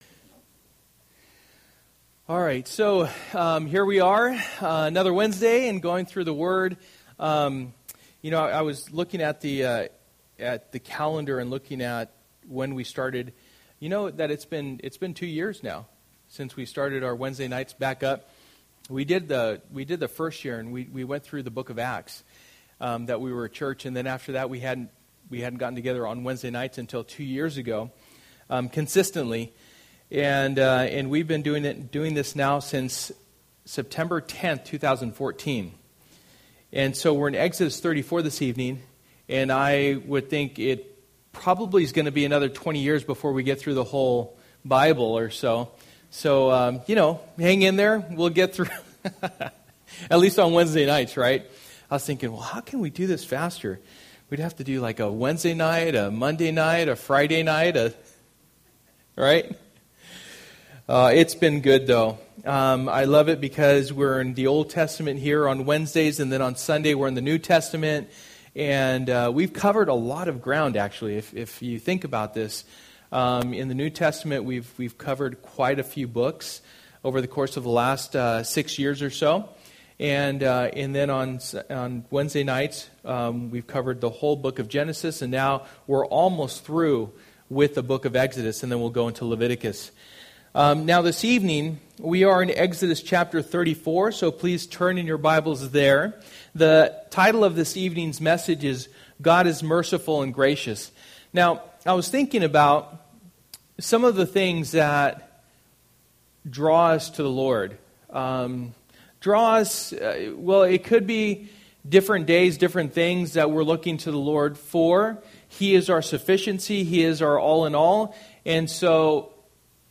Through the Bible Passage: Exodus 34:1-35 Service: Wednesday Night %todo_render% « Jonah 4 Unforgiveness